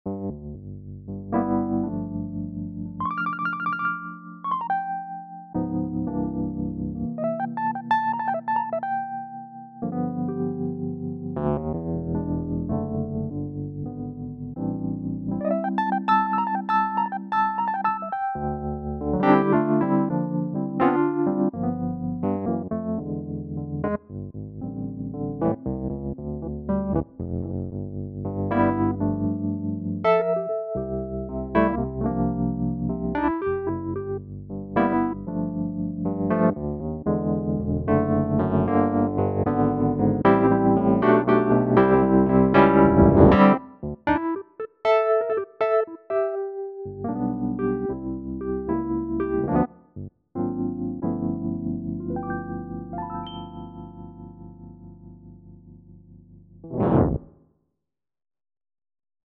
Reed E.Piano
CN301-Reed-E.Piano_.mp3